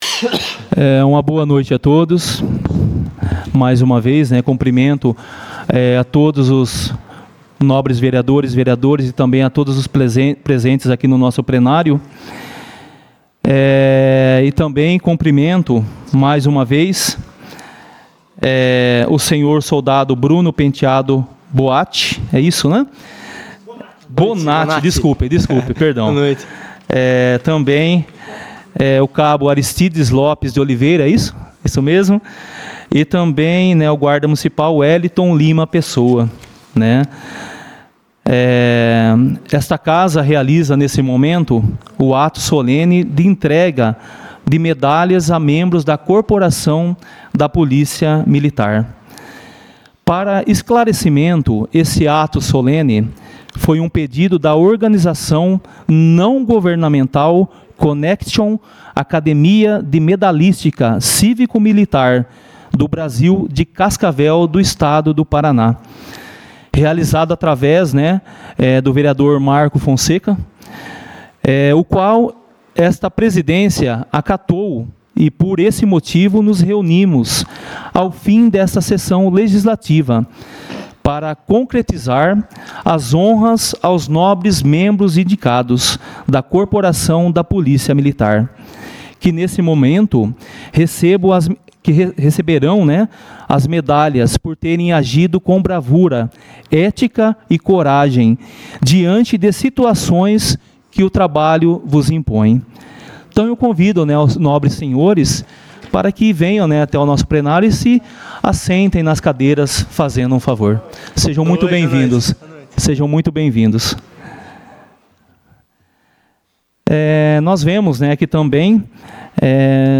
entrega de medalhas